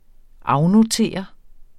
Udtale [ ˈɑwnoˌteˀʌ ]